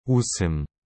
Divida a palavra em duas partes: ut (com um som curto e firme, como “ut” em “but” no inglês) e sum (leve e suave).